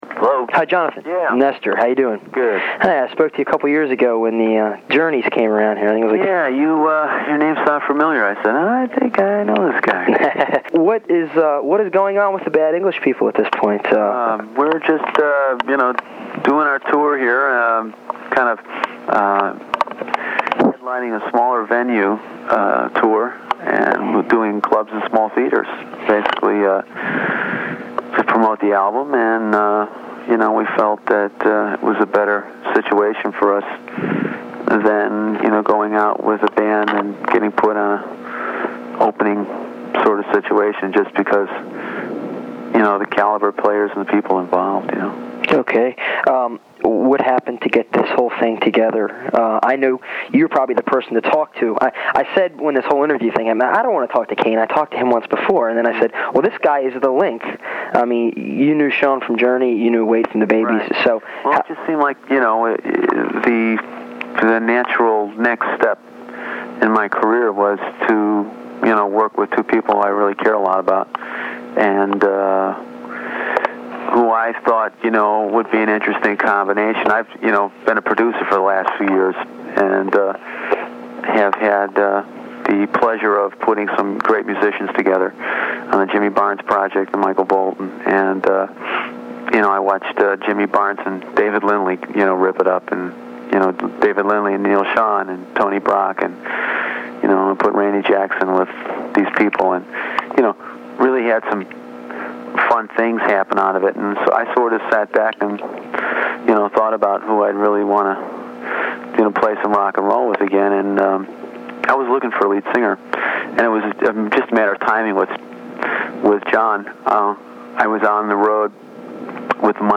Intelligent conversation about all things Baltimore.